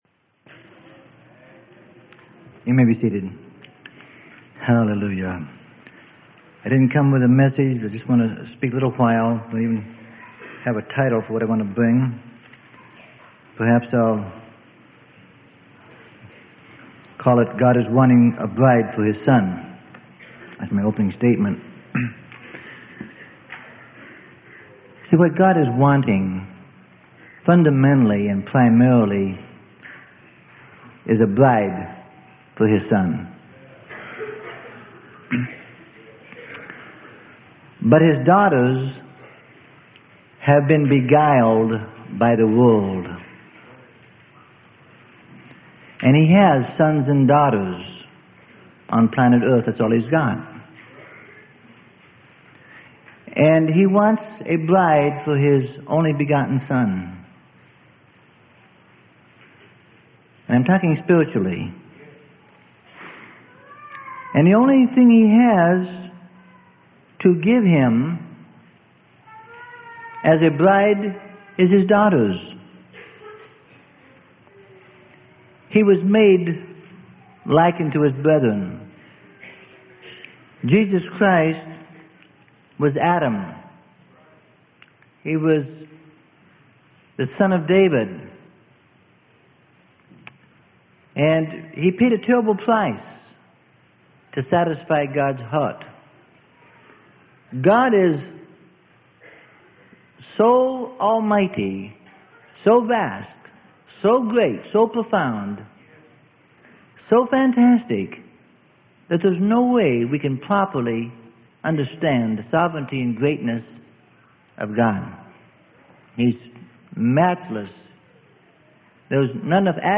Sermon: God is Wanting a Bride for His Son - Freely Given Online Library